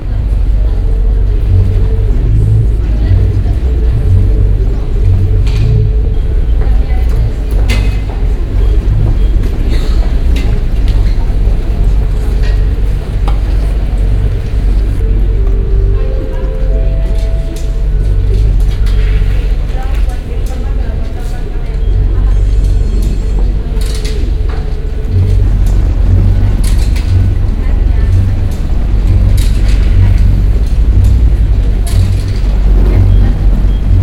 The sounds being played will include various types of breathing and other ambient human noises. For example, steady breathing will turn into faster paced huffing, and a man will clear his throat and go back into some slower breathing. Intertwined with these respiratory sounds will be the shuffling of things. This shuffling should be only recognizable as an object or person moving about, but a listener shouldn’t be able to decipher what they’re listening to other than the breathing.
Shuffling Chaos2.aif